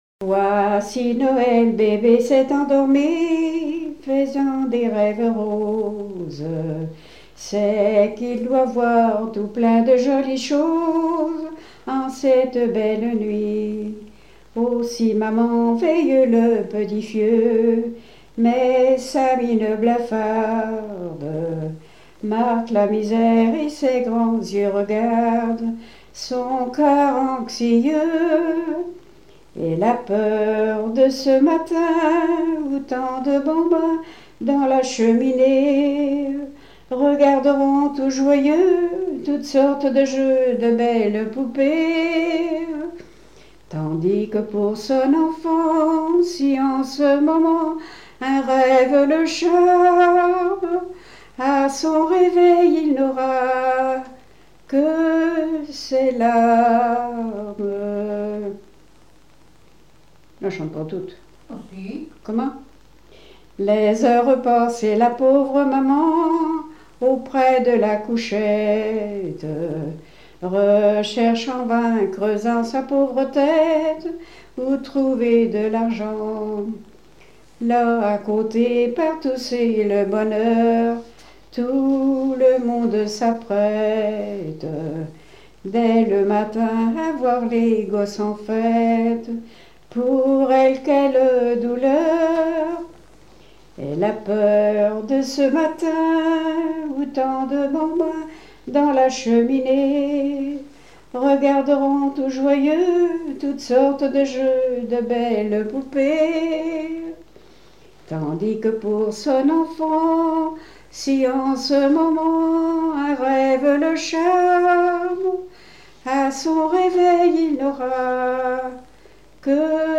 Mémoires et Patrimoines vivants - RaddO est une base de données d'archives iconographiques et sonores.
Chansons de variété
Pièce musicale inédite